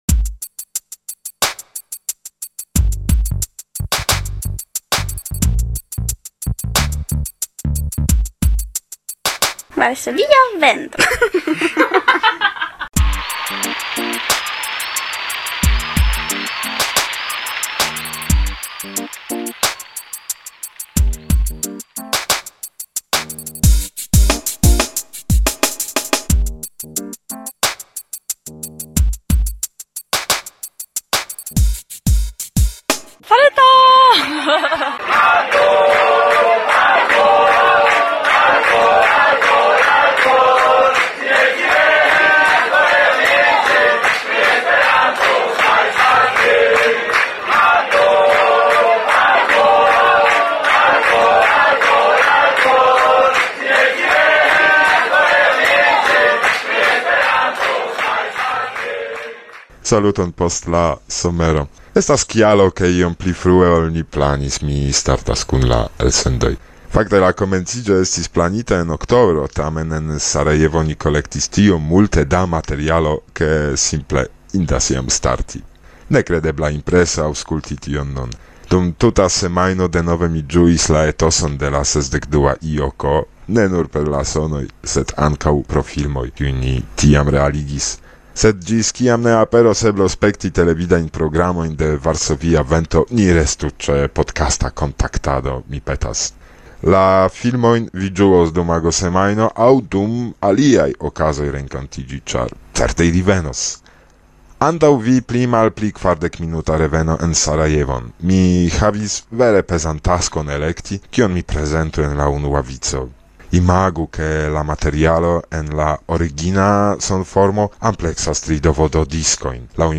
Sonraporto